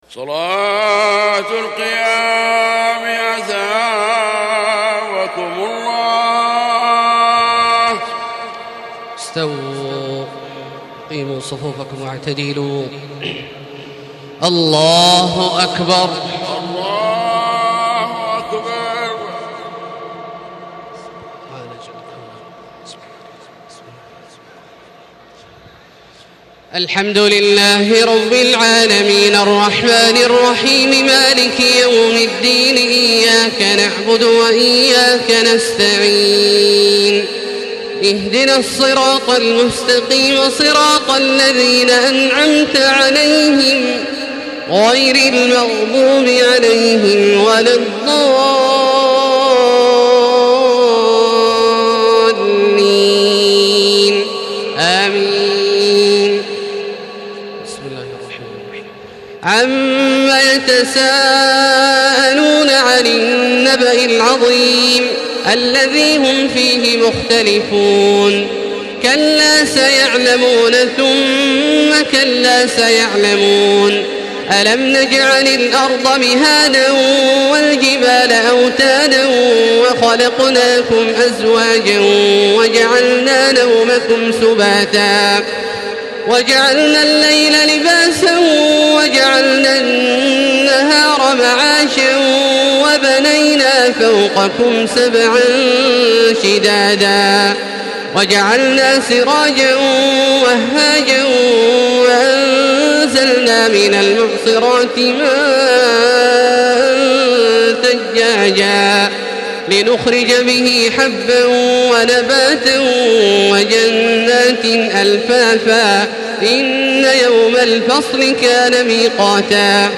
تراويح ليلة 29 رمضان 1435هـ من سورة النبأ الى الليل Taraweeh 29 st night Ramadan 1435H from Surah An-Naba to Al-Lail > تراويح الحرم المكي عام 1435 🕋 > التراويح - تلاوات الحرمين